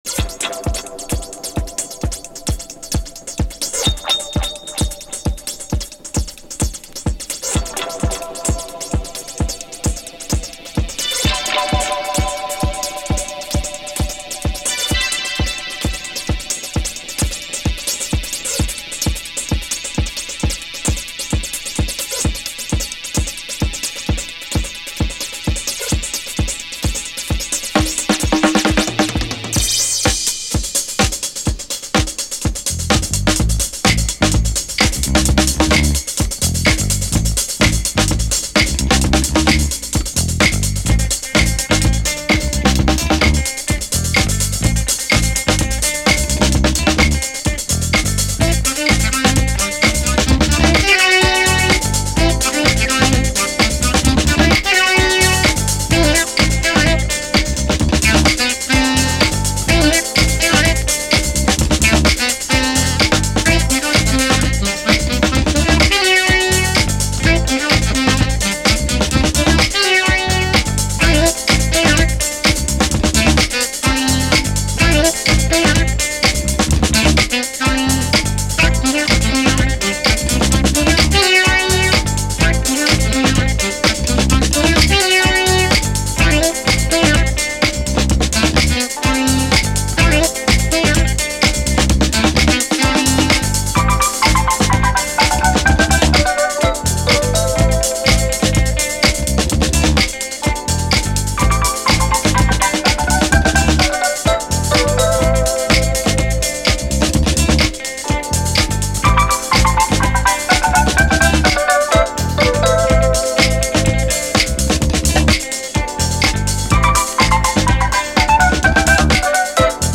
DISCO
LOFT〜ガラージ・クラシックとしても有名なブリット・ファンク〜スペイシー・ブギー・クラシック！